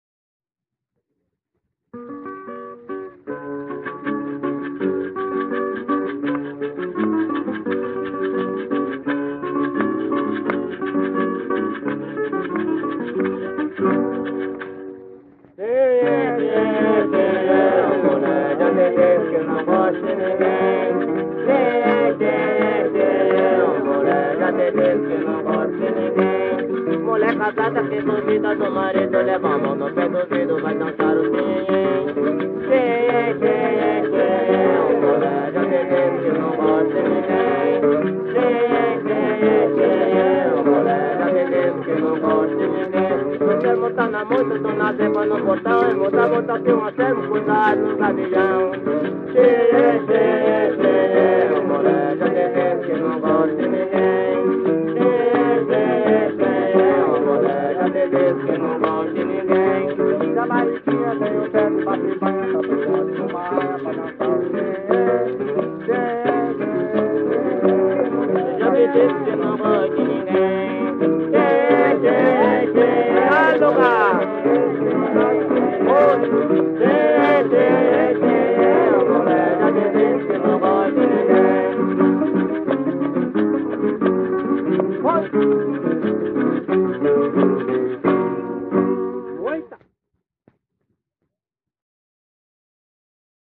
Coco embolada - ""Chenhenhe""